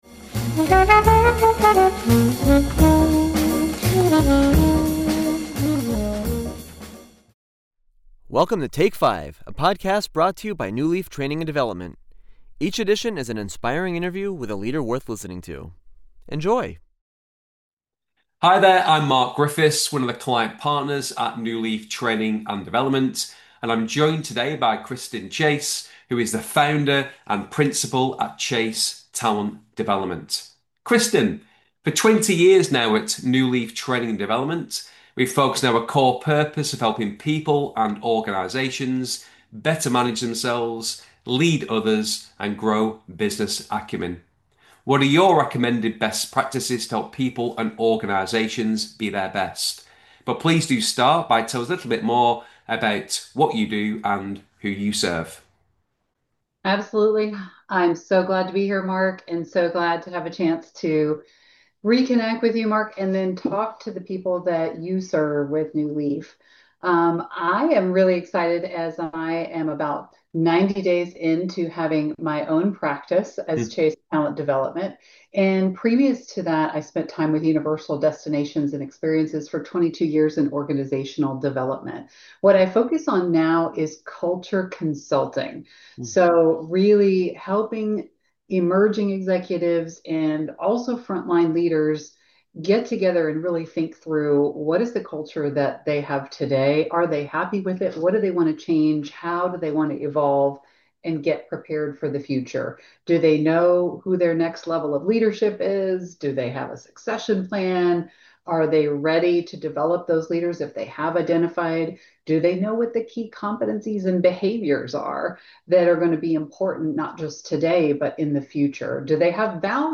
'Going the Extra Mile' will help put a spring in your step while 'Take Five' is an inspiring interview with a leader worth listening to.